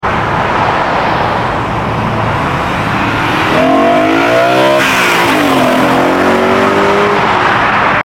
Boosted E92 M3 Leaving Dayton Sound Effects Free Download